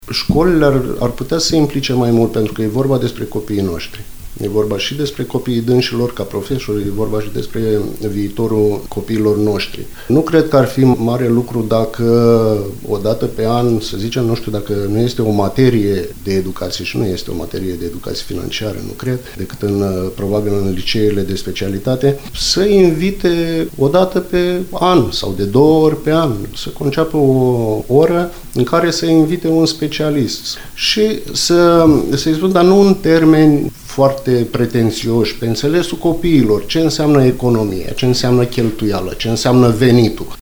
El a declarat, la postul nostru de radio, că aceste deprinderi ar trebui învățate în școală, lucru care nu se întâmplă decât într-o mică măsură.